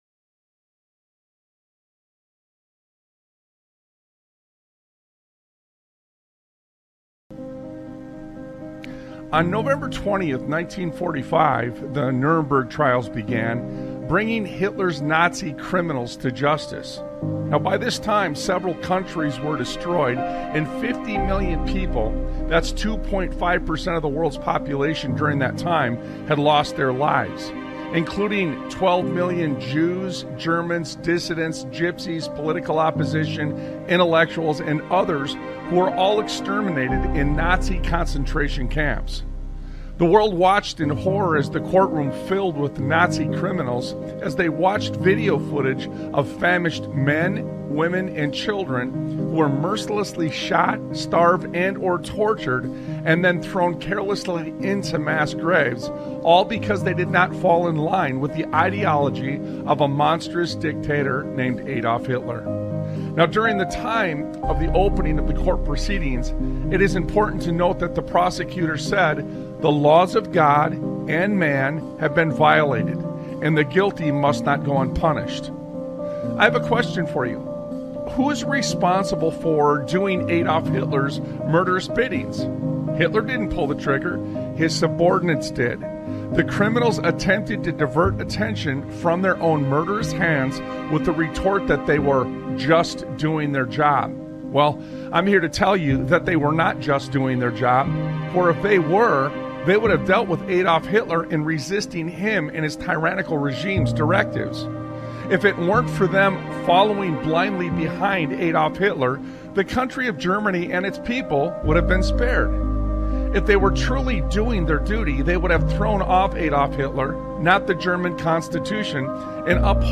Talk Show Episode, Audio Podcast, Sons of Liberty Radio and Mikey Told on Himself on , show guests , about Mikey Told on Himself, categorized as Education,History,Military,News,Politics & Government,Religion,Christianity,Society and Culture,Theory & Conspiracy